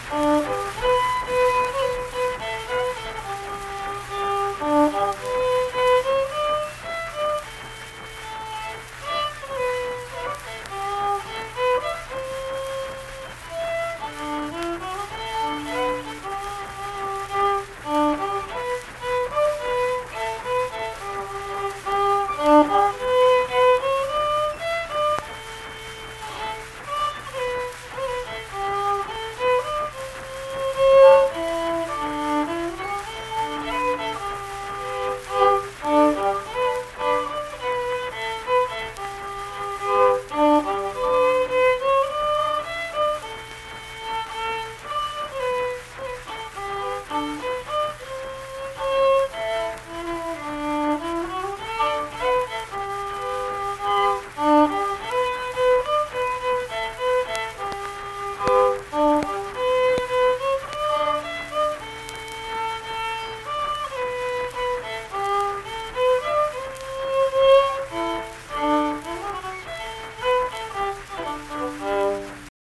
Unaccompanied fiddle music performance
Instrumental Music
Fiddle